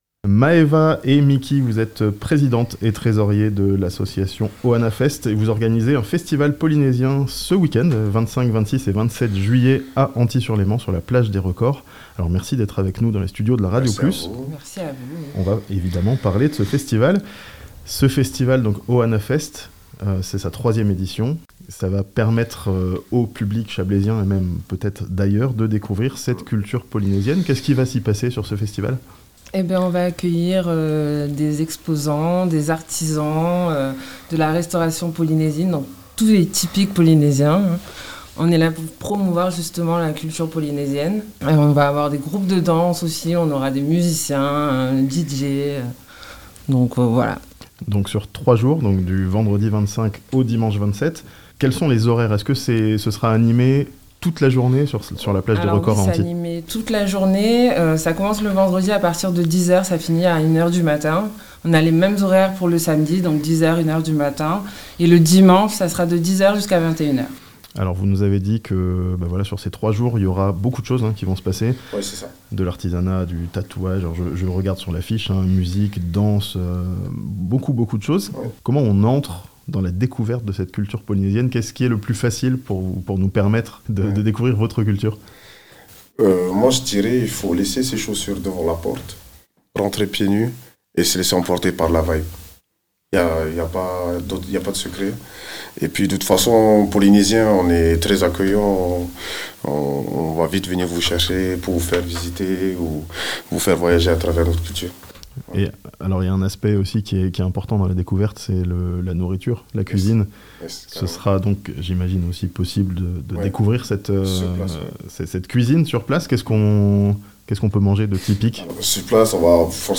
Du Pacifique au Léman, Ohana Fest amène la culture polynésienne dans le Chablais ce weekend (interview)